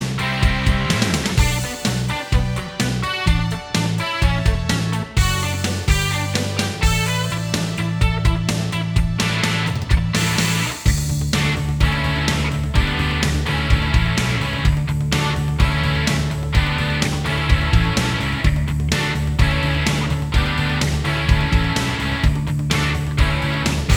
Minus Main Guitars Rock 3:54 Buy £1.50